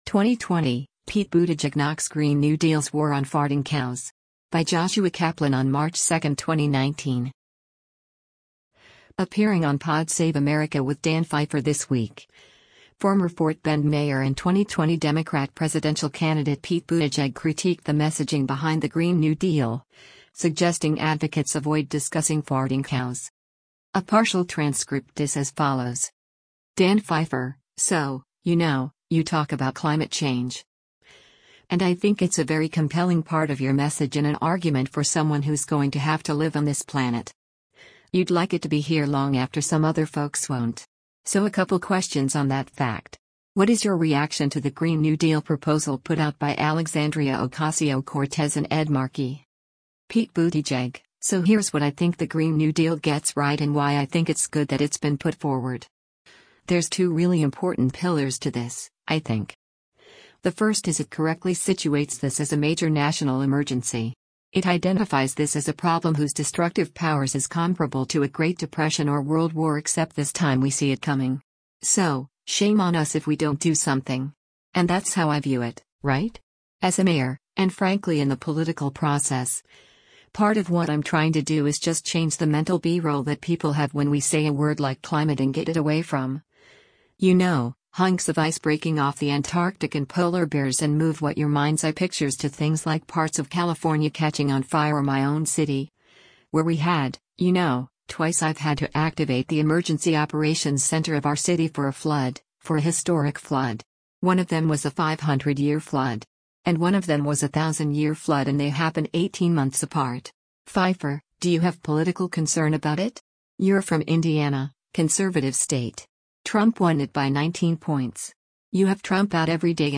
Appearing on Pod Save America with Dan Pfeiffer this week, former Fort Bend mayor and 2020 Democrat presidential candidate Pete Buttigieg critiqued the messaging behind the Green New Deal, suggesting advocates avoid discussing “farting cows.”